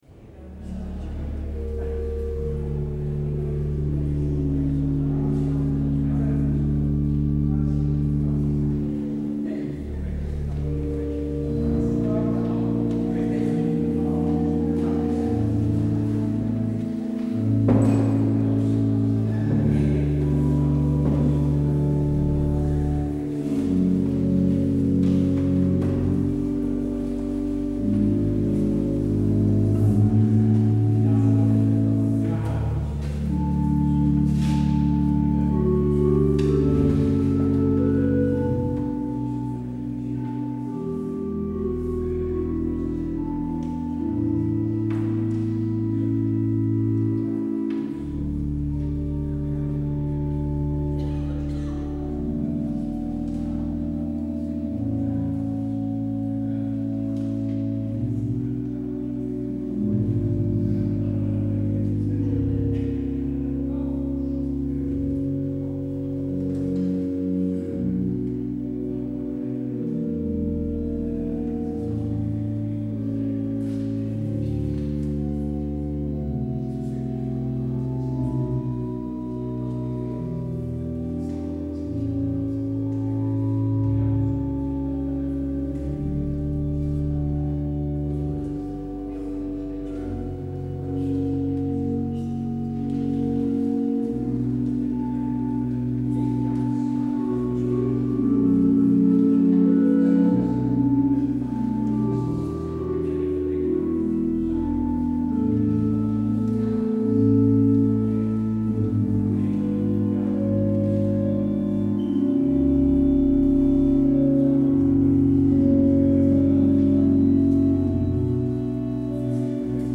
kerkdienst